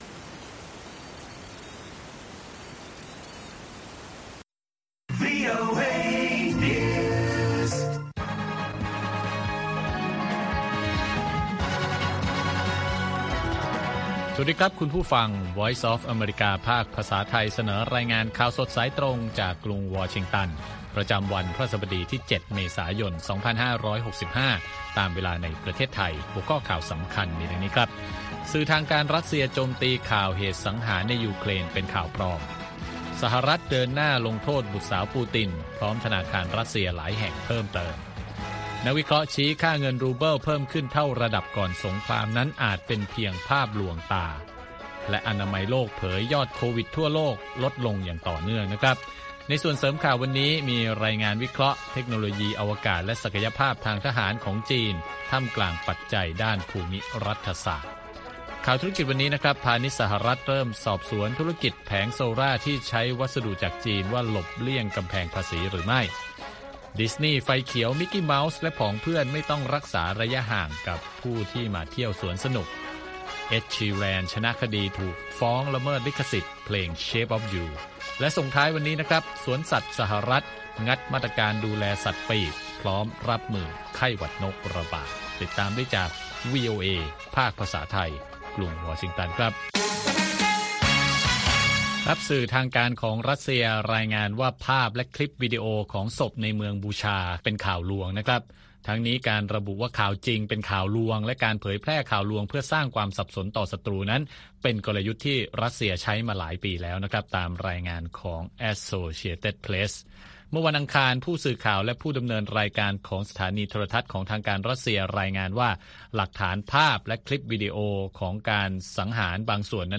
ข่าวสดสายตรงจากวีโอเอ ภาคภาษาไทย 8:30–9:00 น. ประจำวันพฤหัสบดีที่ 7 เมษายน 2565 ตามเวลาในประเทศไทย